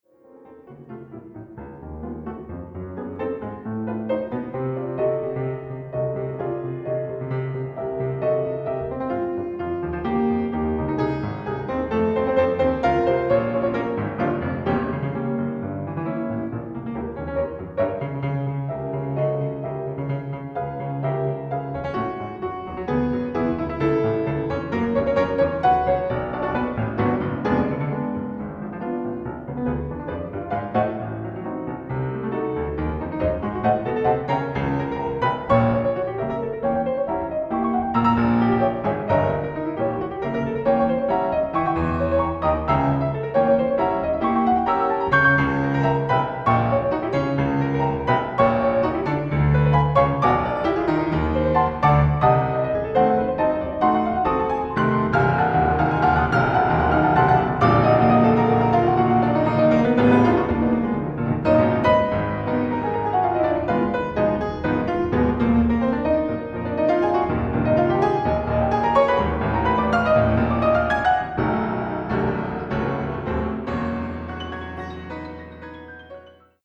Piano transcriptions as you have never heard them before.
studio recording
Classical, Keyboard